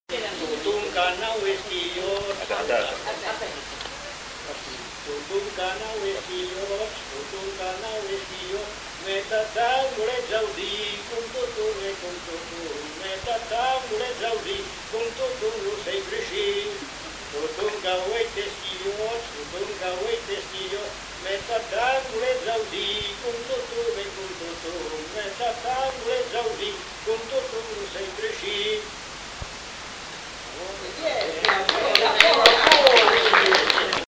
Lieu : Bazas
Genre : chant
Effectif : 1
Type de voix : voix d'homme
Production du son : chanté
Danse : rondeau